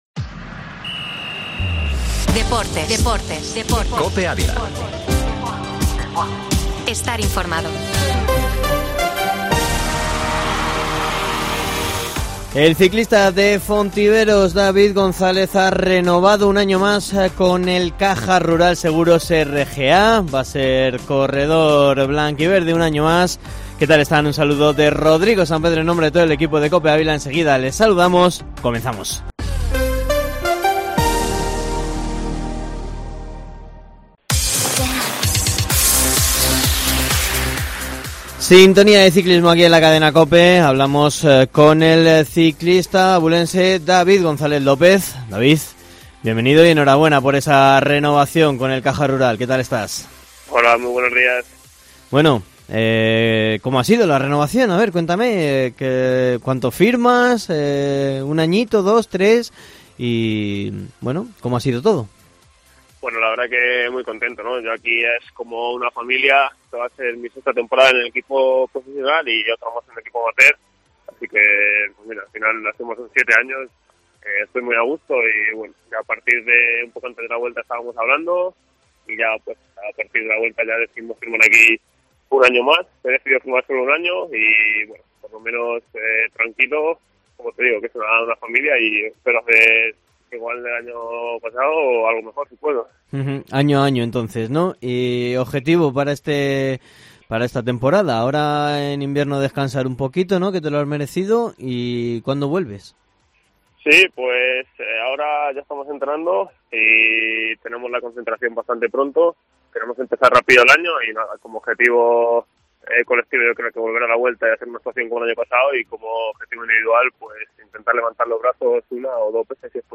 Nos ha contado que su objetivo es volver a ganar, volver a la Vuelta a España y si puede a la Selección Española. (ESCUCHAR ENTREVISTA)